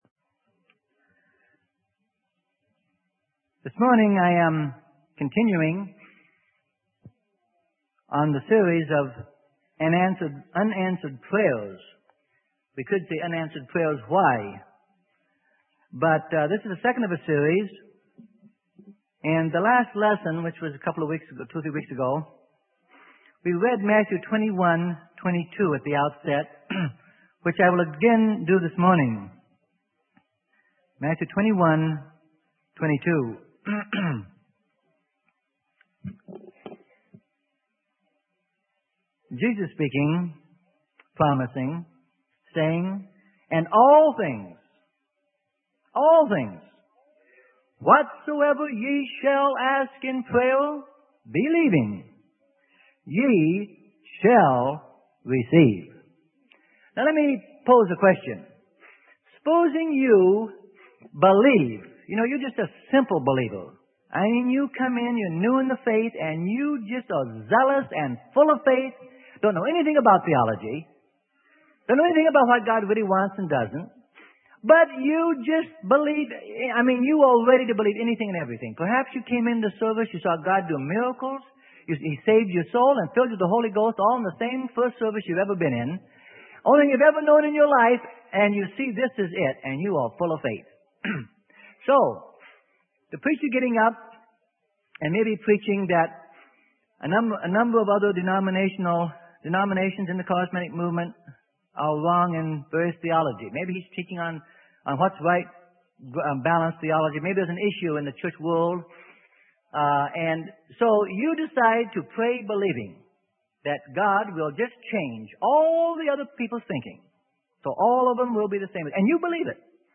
Sermon: Why God Doesn't Seem to Answer Some Prayers - Part 2 - Freely Given Online Library